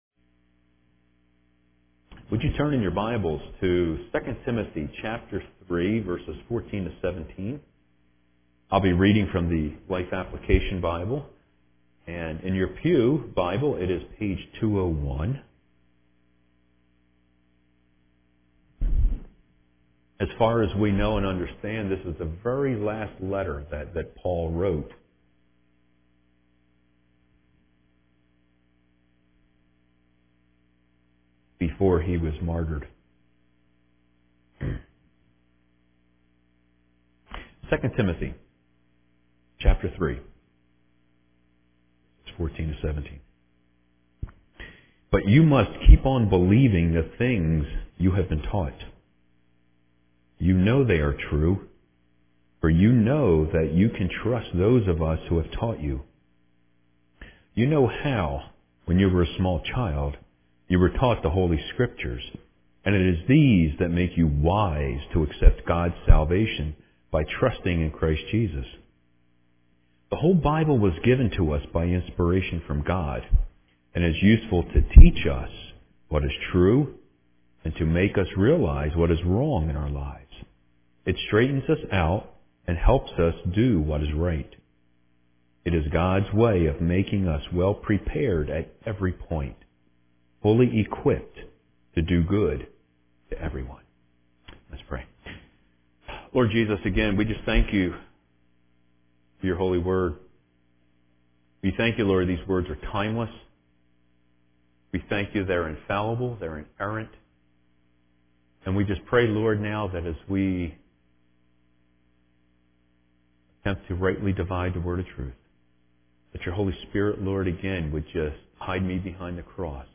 Sunday November 16 was Bible Sunday.